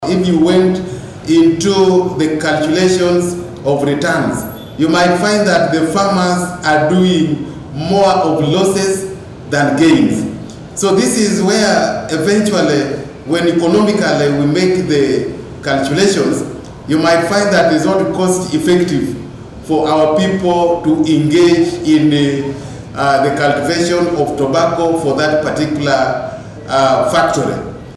Arua District RDC Geoffrey Okiswa Speaks on the returns from tobacco.mp3